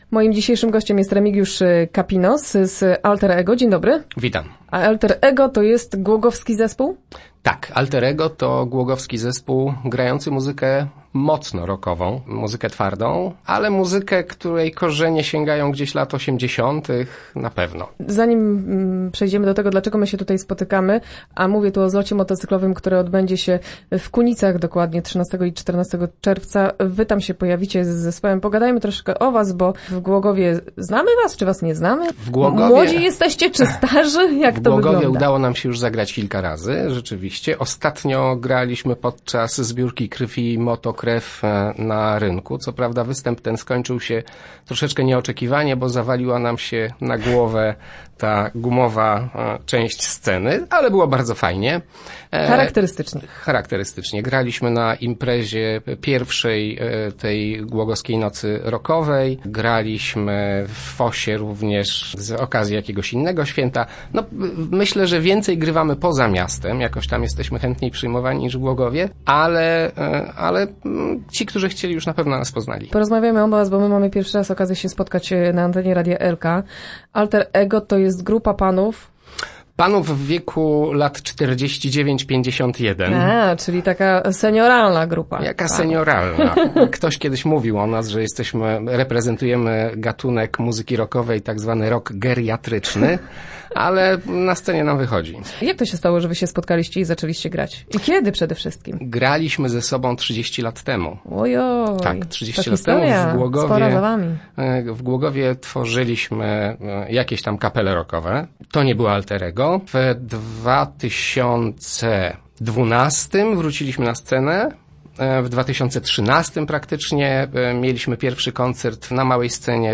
Start arrow Rozmowy Elki arrow Alther Ego na Zlocie Motocyklowym w Legnicy